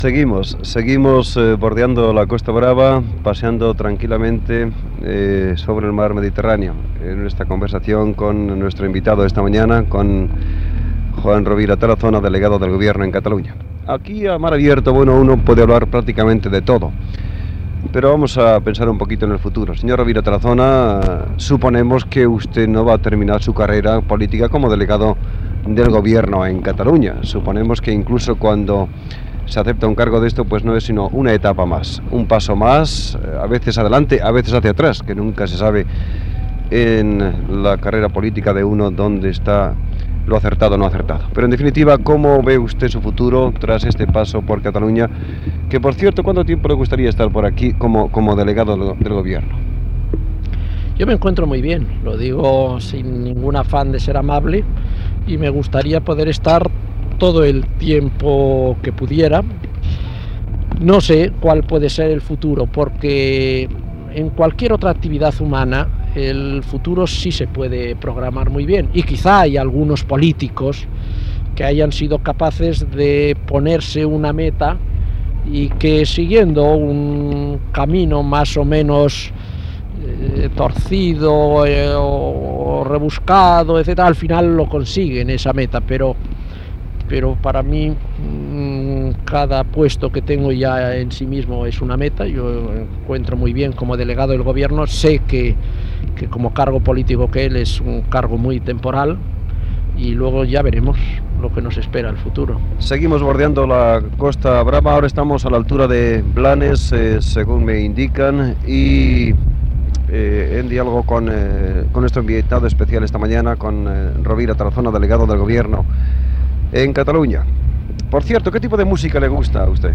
Entrevista al delegat del govern a Catalunya, Juan Rovira Tarazona, navegant per davant de Blanes, sobre com veu el seu futur després de la política i les seves preferències musicals i futbolístiques